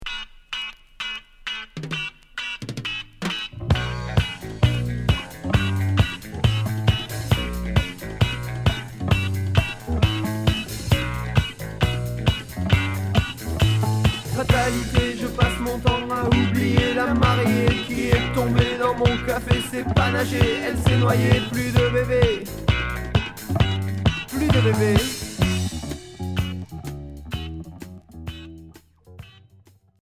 Rock reggae